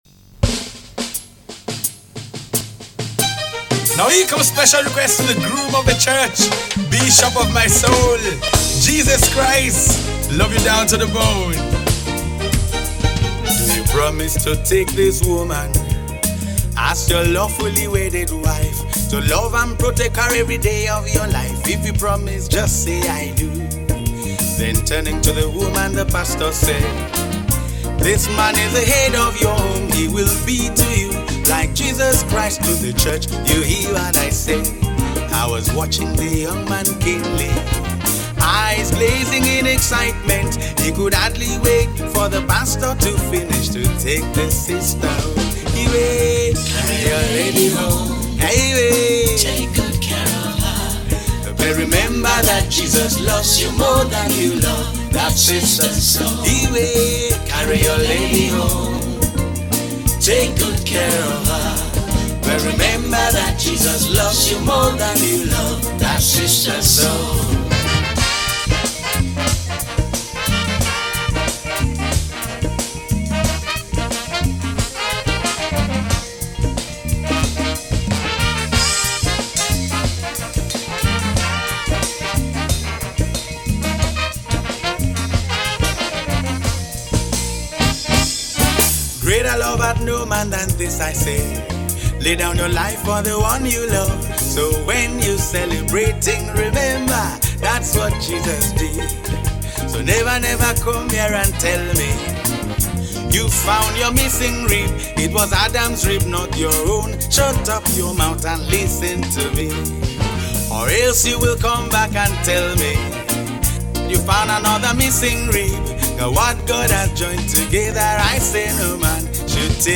March 18, 2025 Publisher 01 Gospel 0